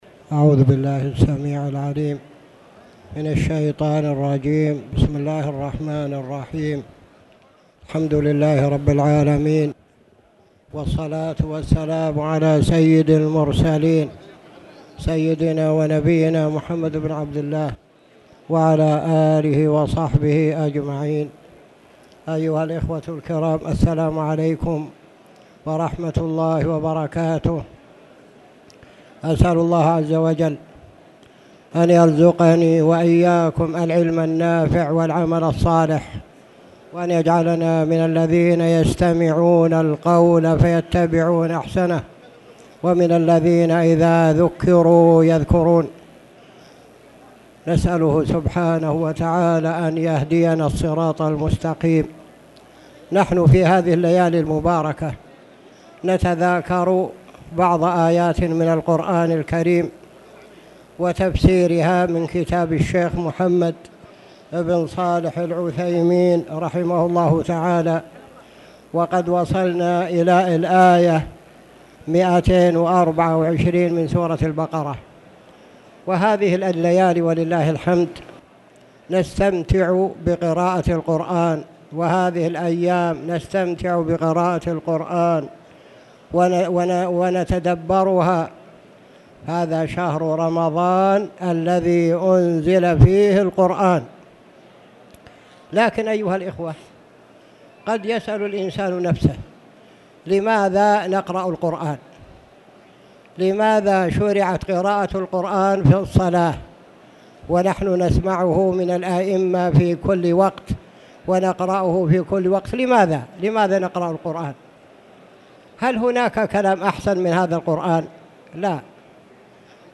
تاريخ النشر ١٧ رمضان ١٤٣٧ هـ المكان: المسجد الحرام الشيخ